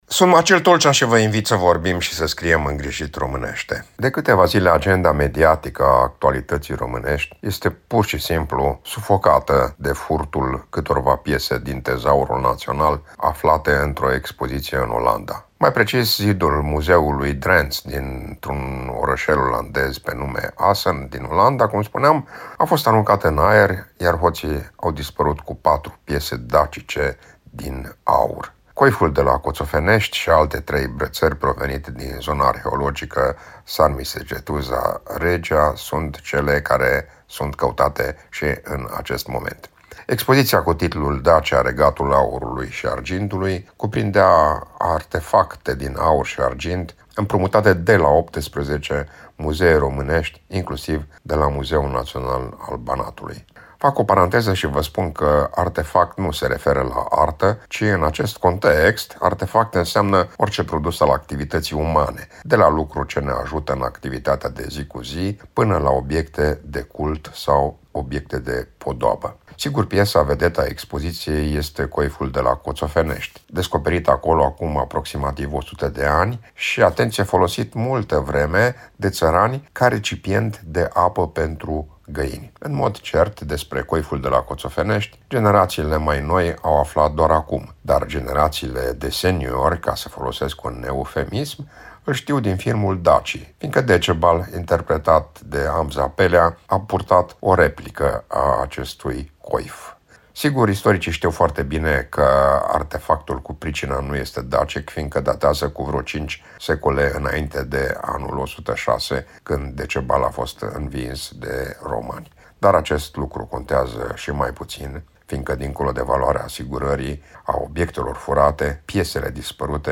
(Rubrică difuzată în 28 ianuarie 2025)